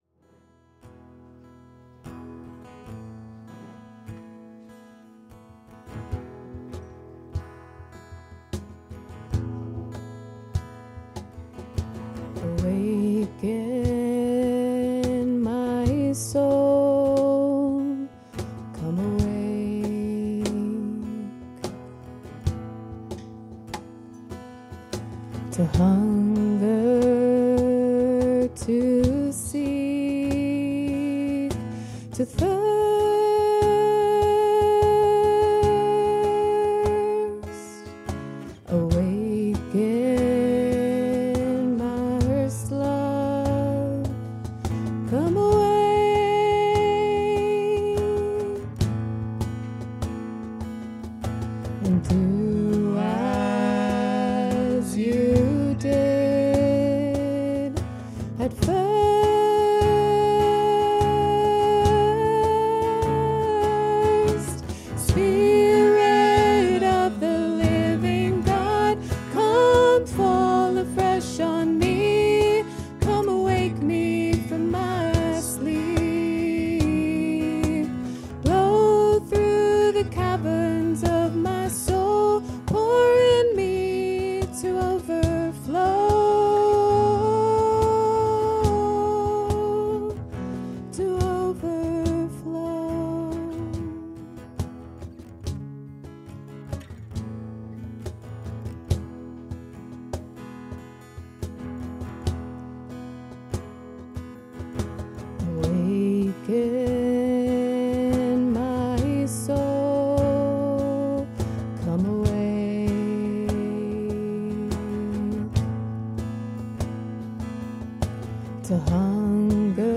Worship 2026-03-08